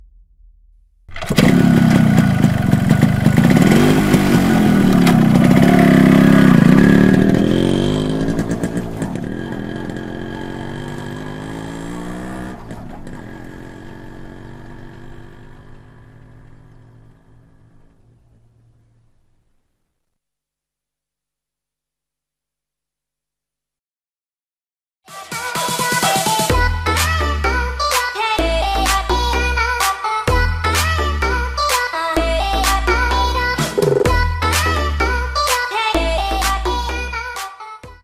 دانلود آهنگ استارت یا هندل موتور و گاز دادن 2 از افکت صوتی حمل و نقل
جلوه های صوتی
دانلود صدای استارت یا هندل موتور و گاز دادن 2 از ساعد نیوز با لینک مستقیم و کیفیت بالا